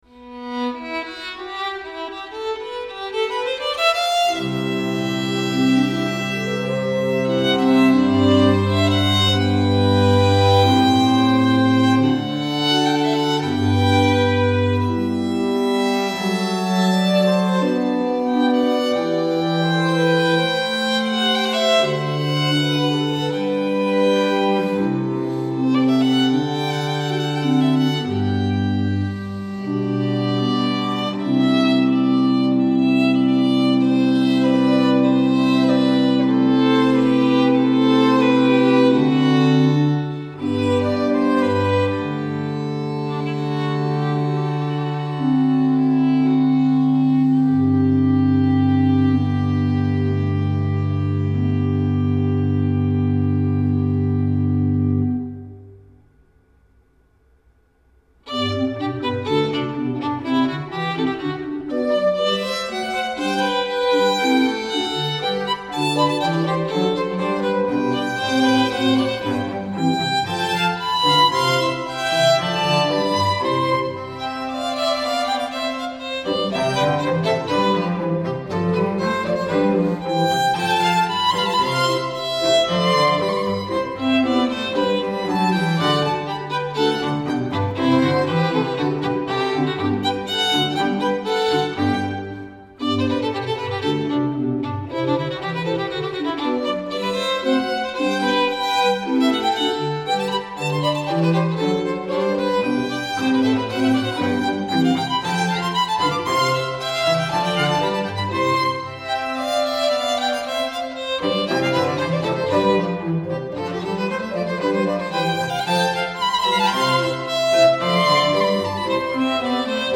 Vi riproponiamo oggi quella conversazione.